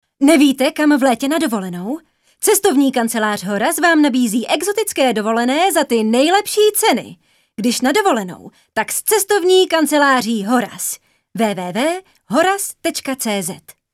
ukázka reklama
ukazka-reklama.mp3